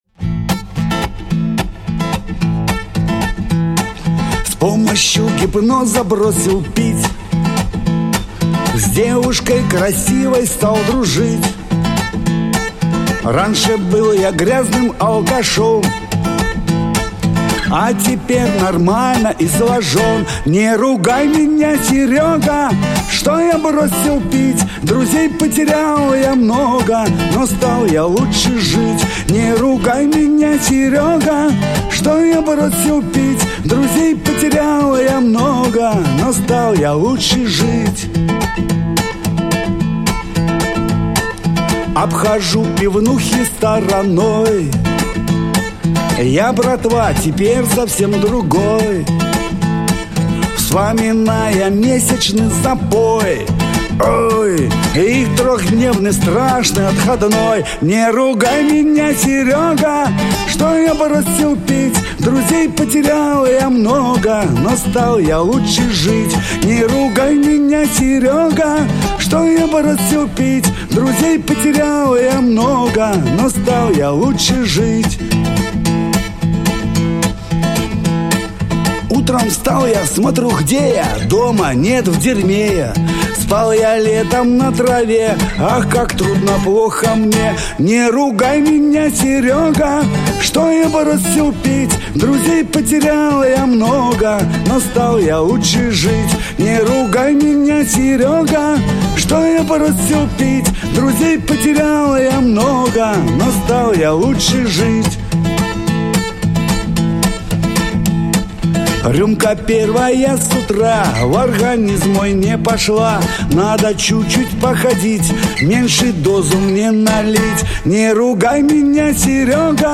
Дворовые под гитару